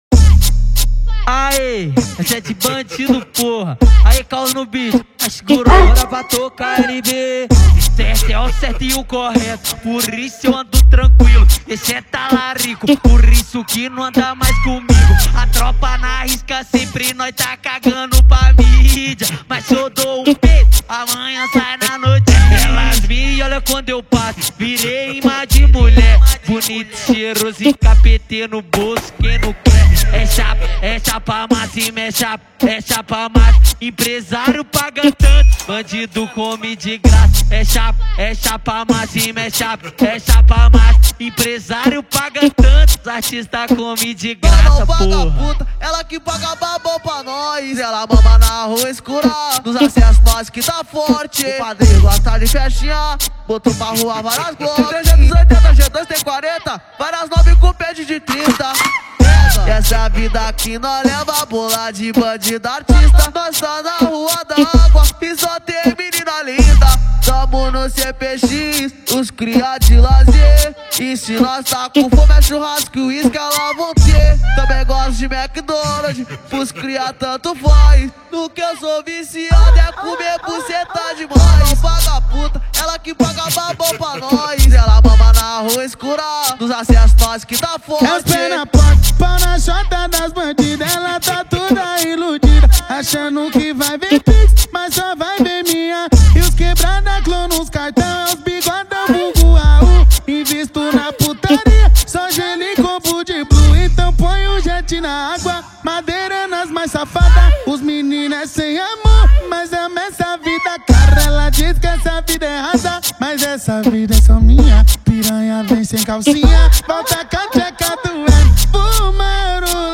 2024-12-10 18:09:44 Gênero: Funk Views